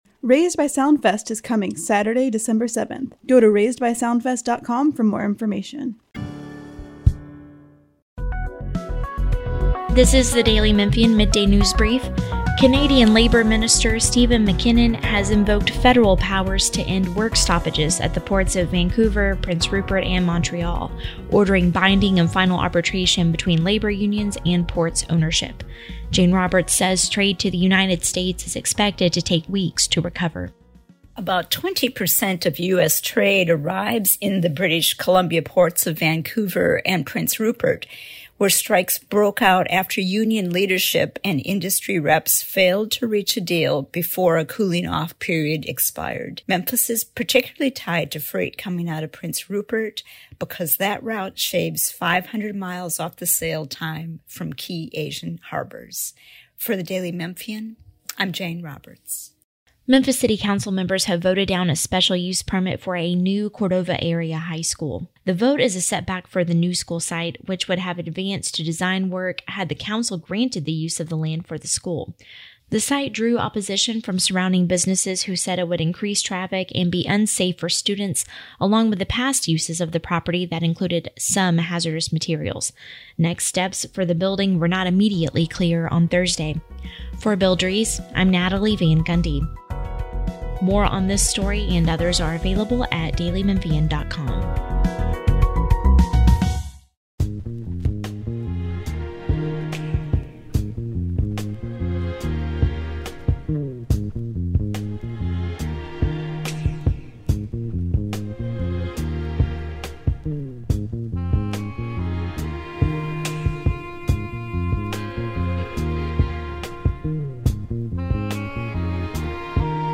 Blues Share: Copied!